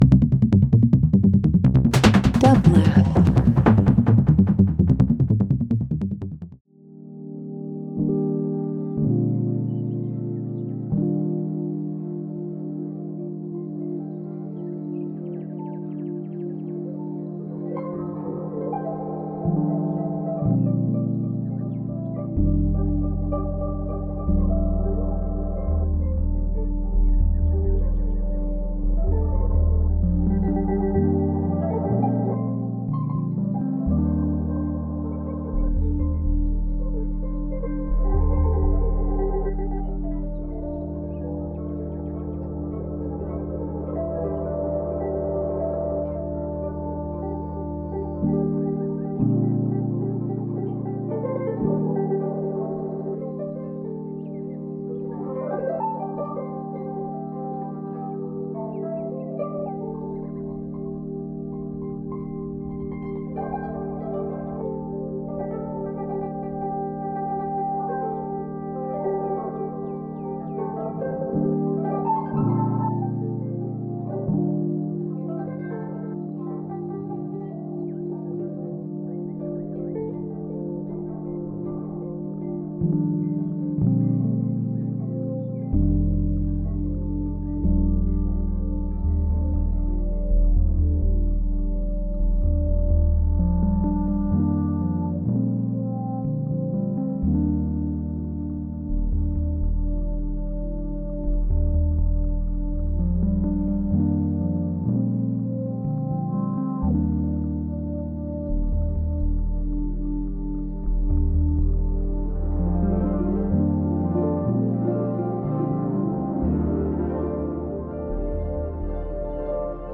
Ambient Experimental Fourth World Instrumental